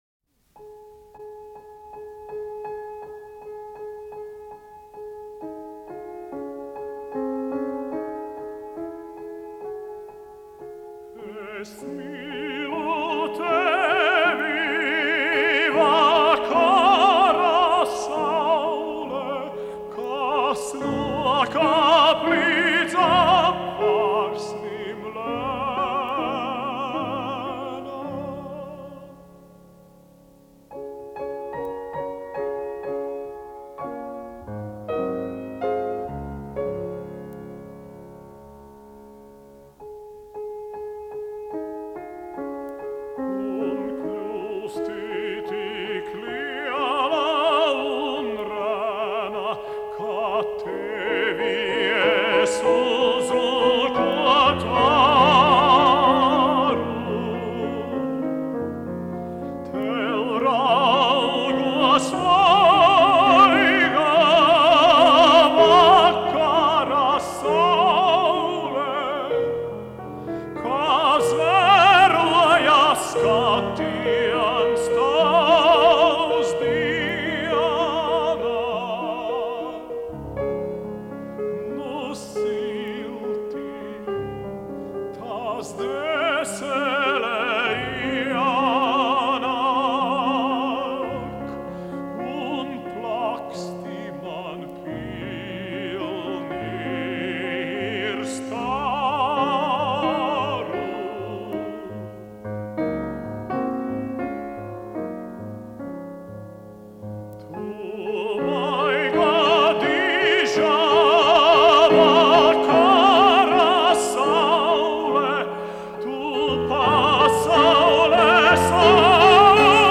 Simon, Rita J., aranžētājs
Mūzikas ieraksts